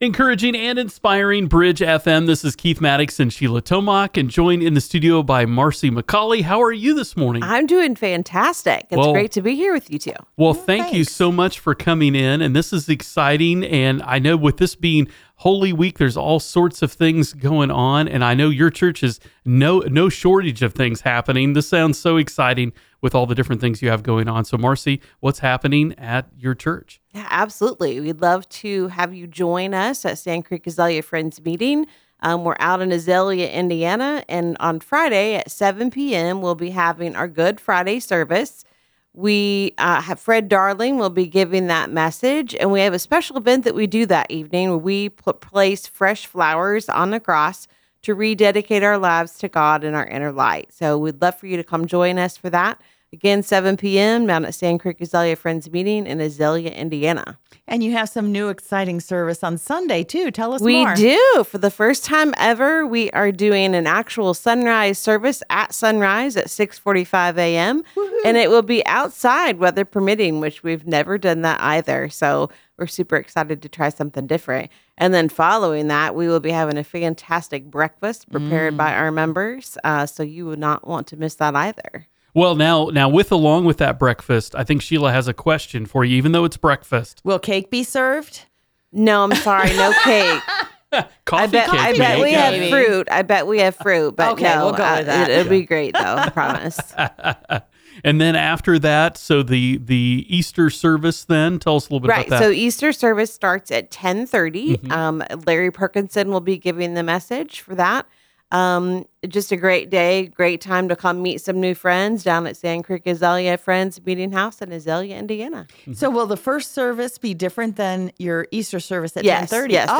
Sandcreek Azalea Friends Holy Week Services Interview